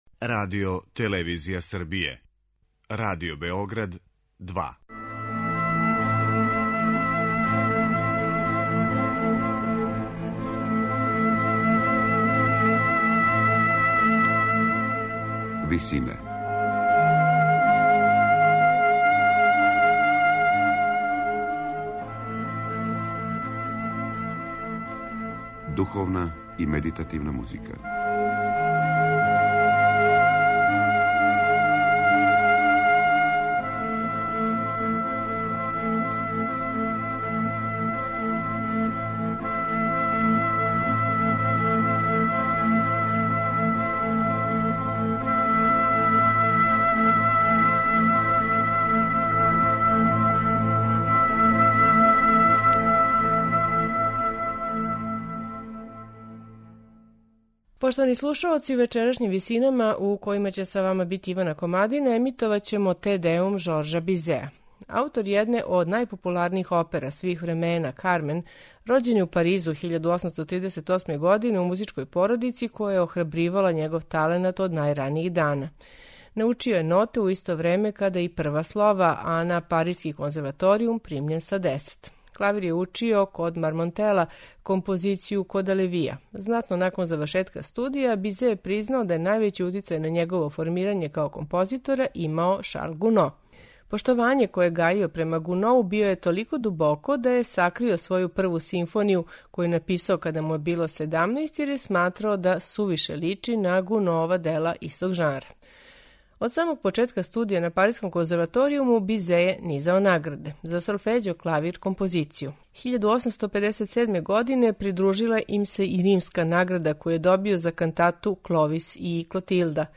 Сасвим очекивано, највећи део партитуре ове композиције прожет је оперским духом, што се одразило и на деонице солиста, које су технички неупоредиво захтевније од хорских.
сопран
тенор
бас